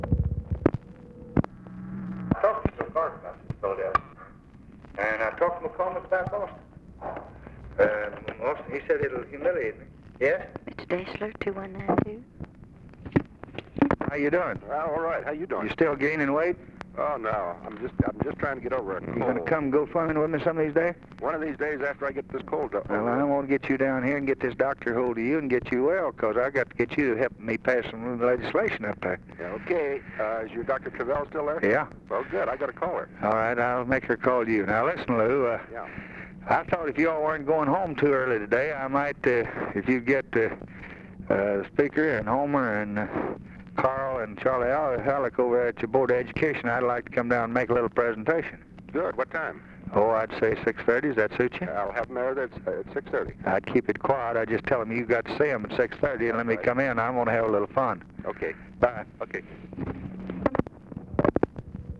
Conversation with LEW DESCHLER, December 10, 1963
Secret White House Tapes